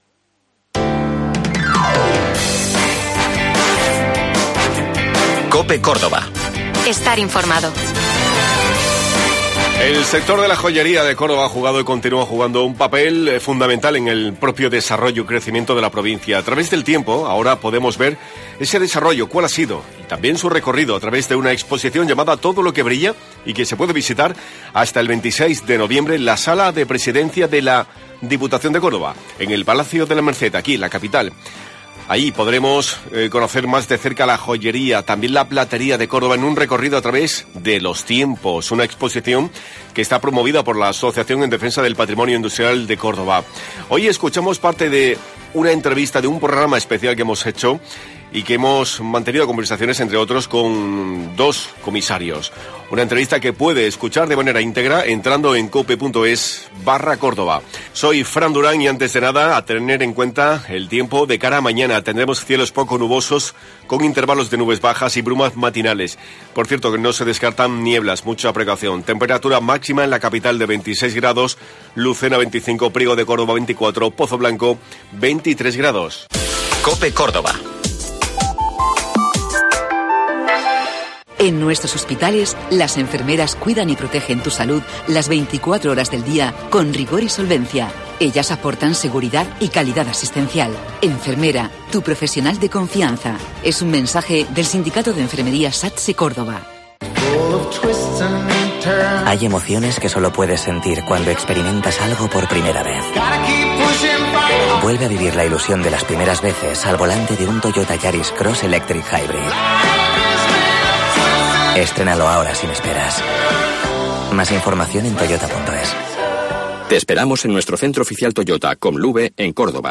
La Galería de Presidencia del Palacio de la Merced, sede de la diputación de Córdoba, acoge estos días una exposición titulada "Todo lo que brilla". Desde el 27 de octubre y hasta el 26 de noviembre, la sociedad tiene la oportunidad de hacer un "recorrido por la joyería y la platería a lo largo de los años", como ha explicado en el programa especial que COPE producido, Gabriel Duque, delegado de Cultura de la Institución provincial.